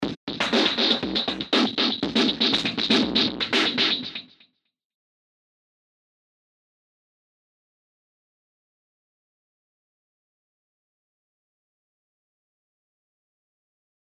ここでは内蔵エフェクトのフィルターやディレイを使用した参考例を紹介します。
図5：図4の状態でSTYLUS RMX上でエフェクトのサウンドメイクを行なった設定例。